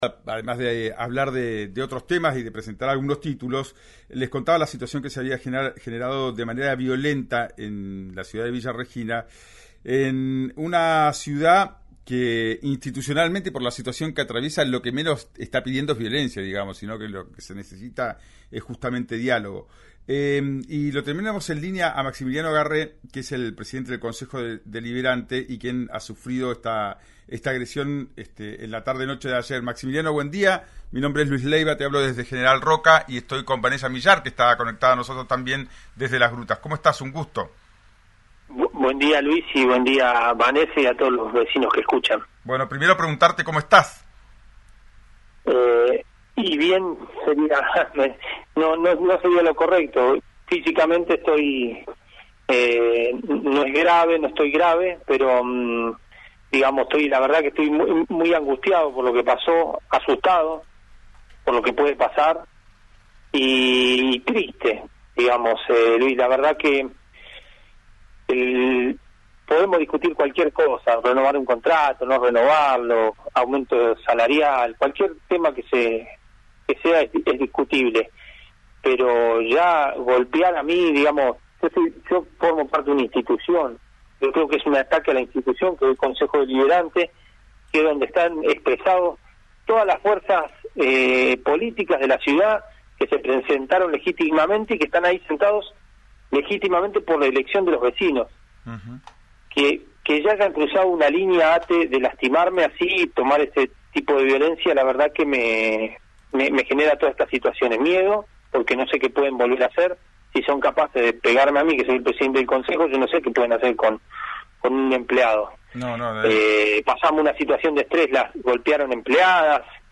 El presidente del Concejo Deliberante dialogó en RÍO NEGRO RADIO, sobre las agresiones que recibió por parte de un manifestante de ATE.